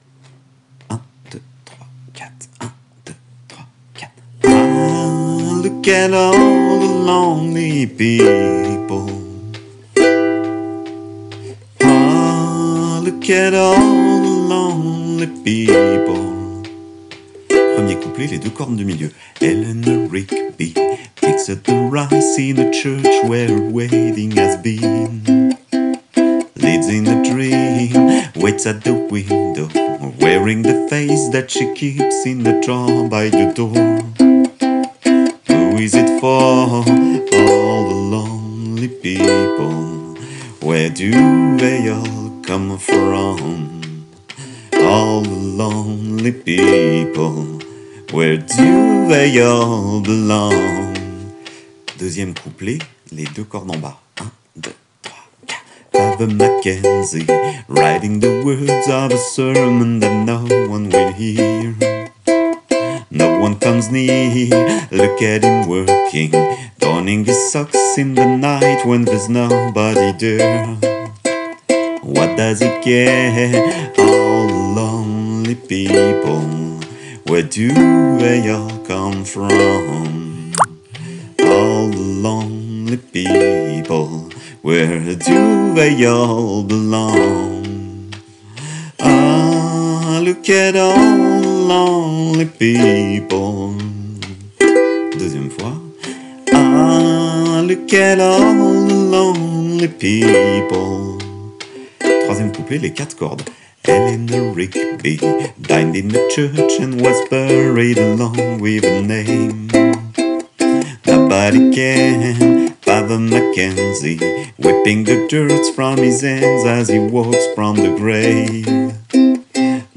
Vitesse normale :